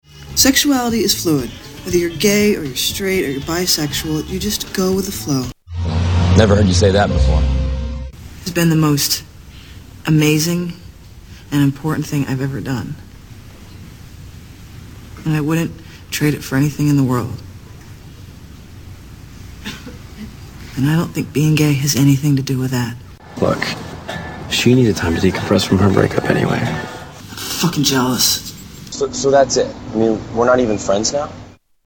Tags: Soundboards Talk to each other Mash up Mash up clips Mash up sounds Soundboard talking